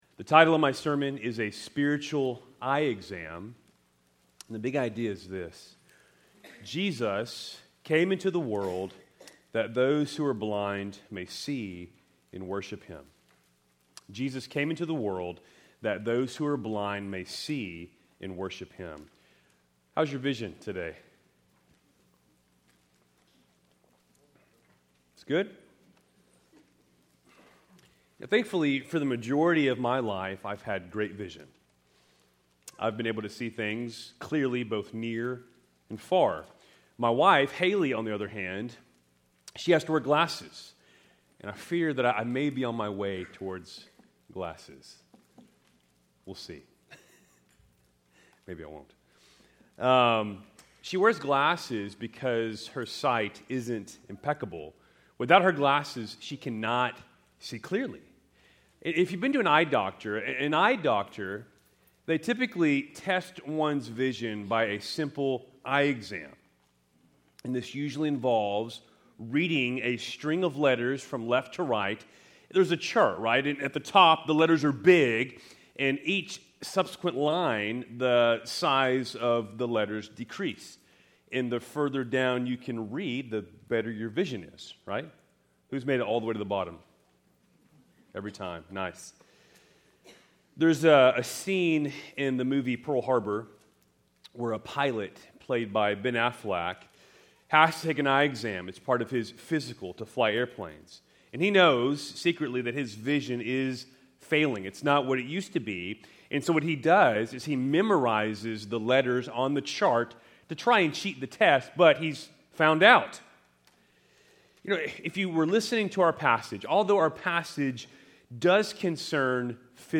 Keltys Worship Service, December 1, 2024